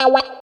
134 GTR 1 -R.wav